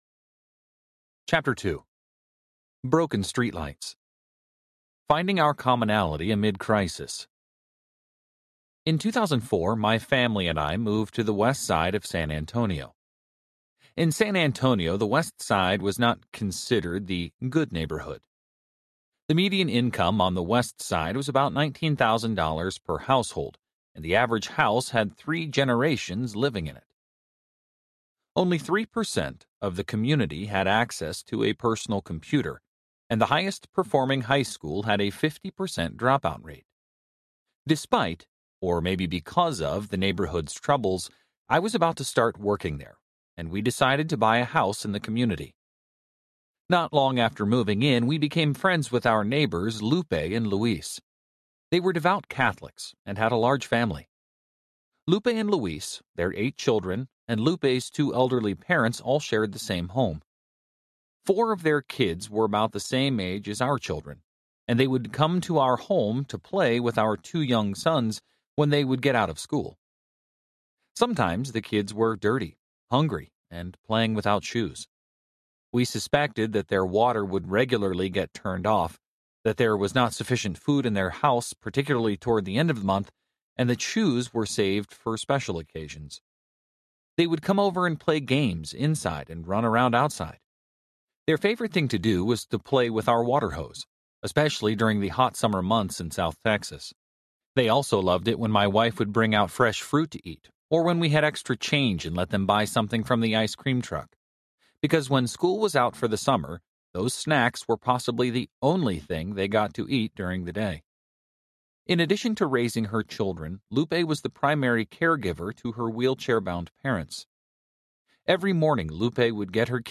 I Was Hungry Audiobook
Narrator
5.0 Hrs. – Unabridged